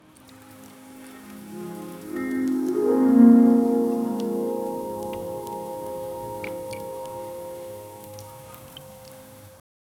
Soft ambient nature sounds with gentle wind and tea pouring, layered with light bamboo flute or acoustic piano for a calm, elegant mood.
soft-ambient-nature-sound-mgqwfmfl.wav